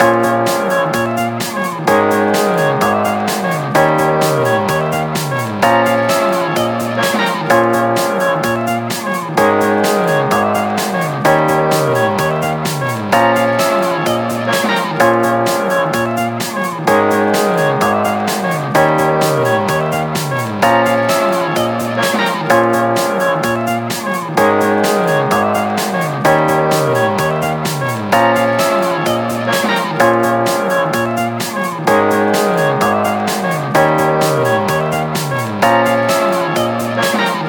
SWING